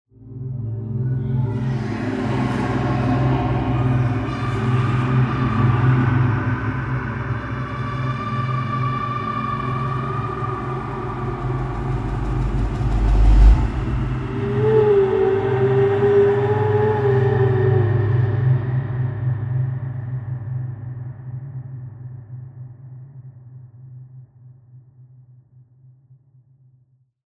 Sound Effect Horror Intro 6.mp3